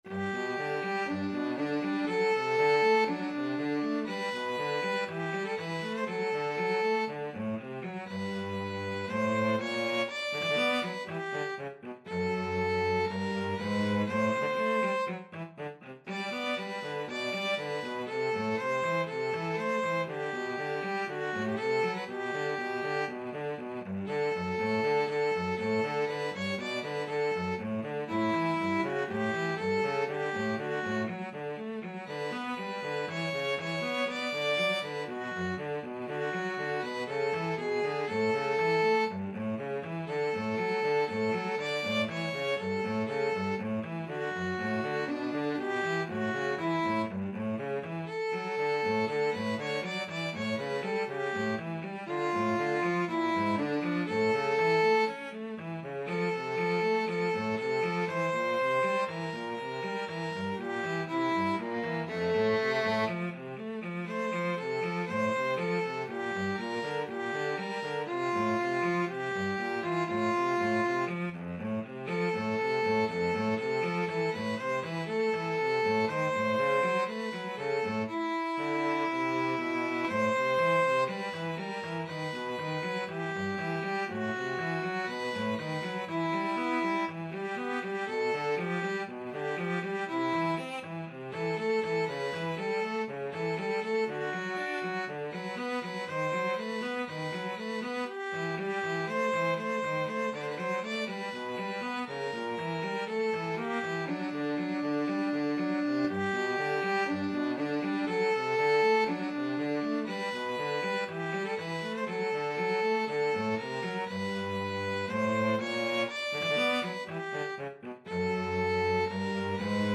adagio Slow =c.60
2/4 (View more 2/4 Music)
Classical (View more Classical Violin-Cello Duet Music)